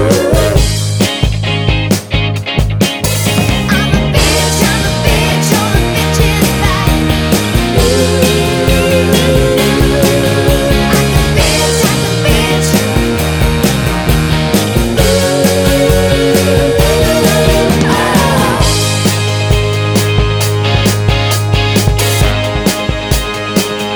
End Cut Down Christmas 3:44 Buy £1.50